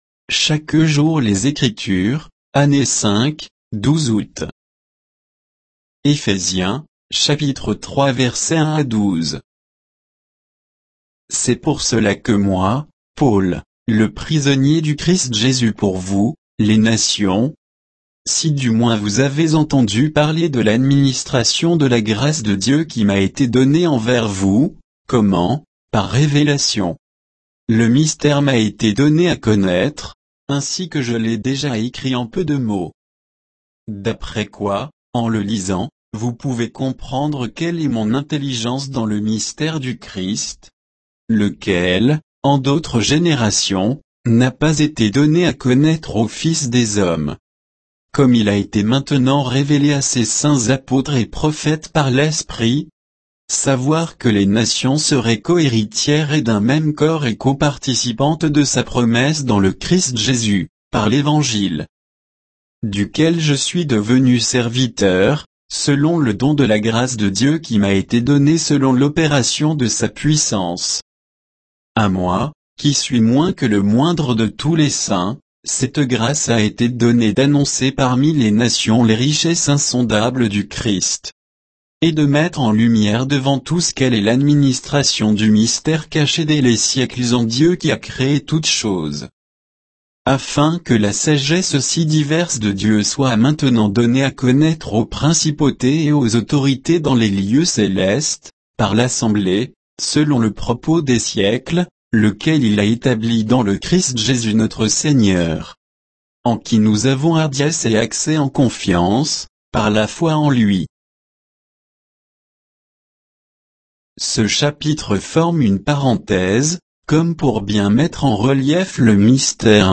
Méditation quoditienne de Chaque jour les Écritures sur Éphésiens 3